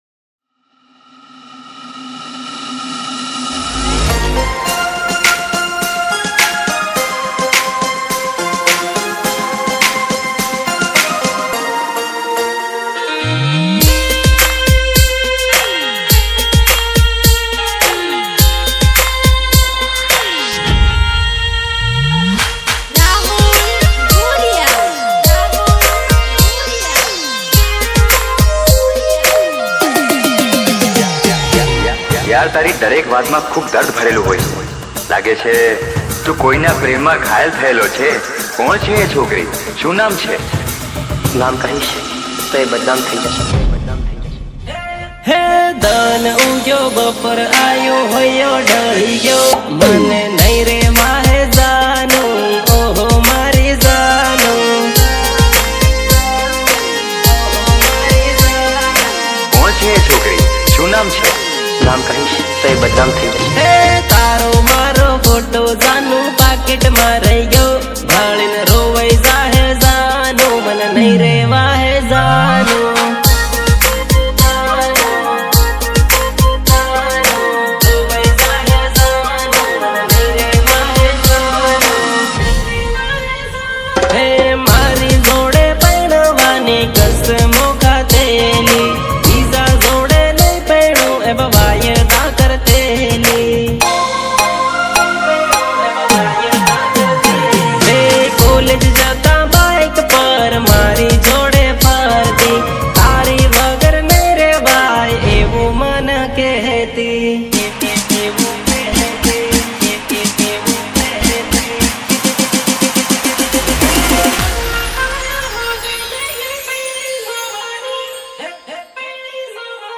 Category: GUJARATI NON STOP GARBA SONG